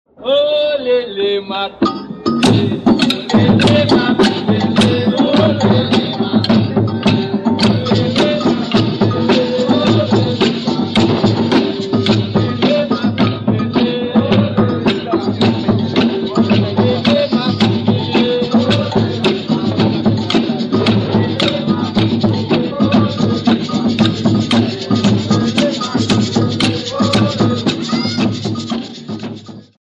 Maculelê
Os bastões são batidos uns nos outros, em ritmo firme e compassado. Essas pancadas presidem toda a dança, funcionando como marcadores do pulso musical. A banda que anima o grupo é composta por atabaques, pandeiros e, às vezes, violas de 12 cordas. As cantigas são puxadas pelo macota e respondidas pelo coro.
autor: Maculelê de Santo Amaro da Purificação, data: 1957
maculele.mp3